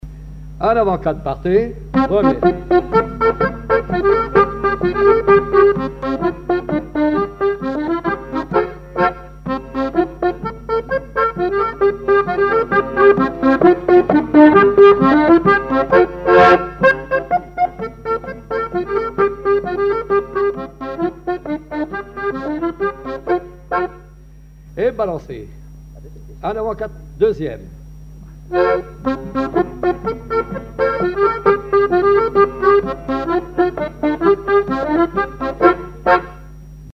danse : quadrille : avant-quatre
collectif de musiciens pour une animation à Sigournais
Pièce musicale inédite